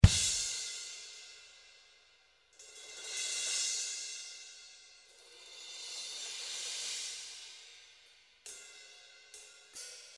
71 BPM - Natural Spring (35 variations)
71 bpm song, that has 35 variations.